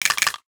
NOTIFICATION_Rattle_13_mono.wav